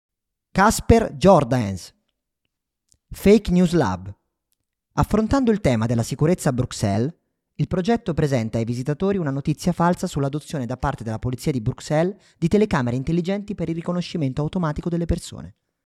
È un’installazione basata sulla conversazione tra due AI e il visitatore. Ha l’obiettivo di aiutare a comprendere come vengono creati immagini e testi fittizi.